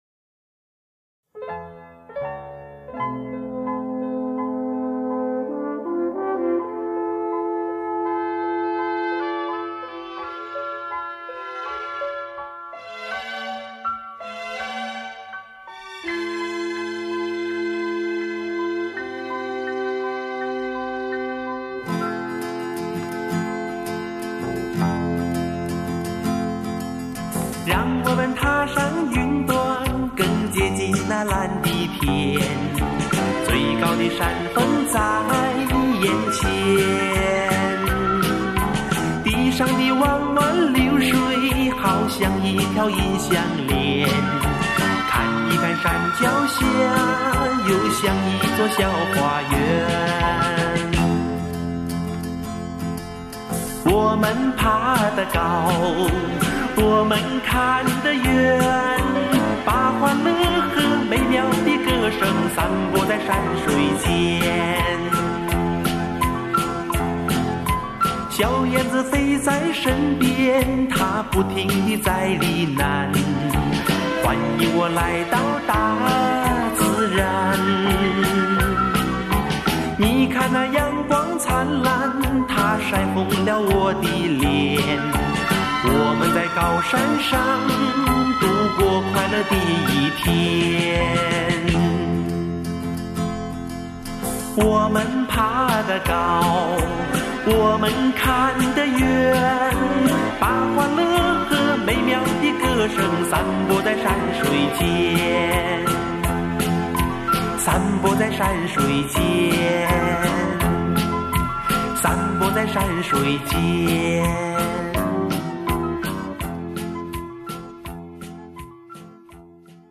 现在看来，这盒专集真没有什么可取的地方，全是翻唱了一些当时的港台流行歌曲。